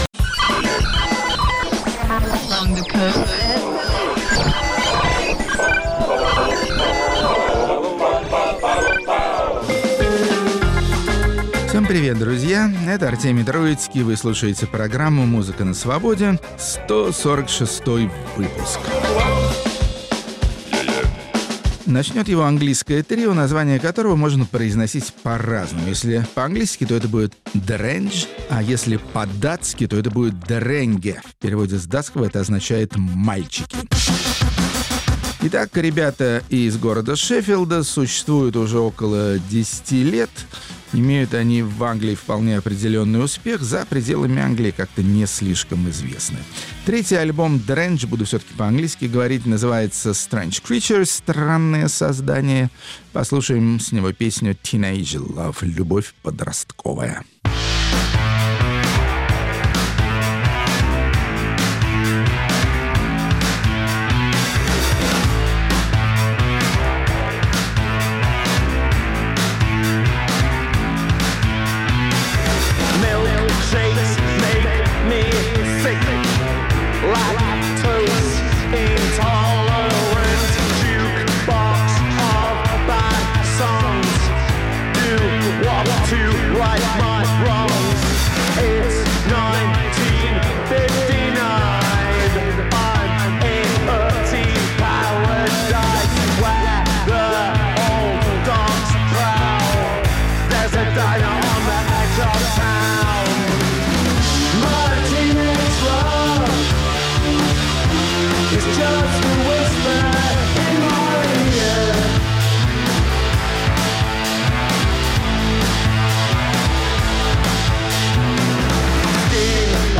Музыка на Свободе. 23 февраля, 2020 Исполнители, не оставляющие надежды вдохнуть новую жизнь в рок-жанр, который, как казалось полвека назад, должен был перевернуть все и всяческие представления о прекрасном. Рок-критик Артемий Троицкий внимательно следит за попытками гальванизировать progressive rock.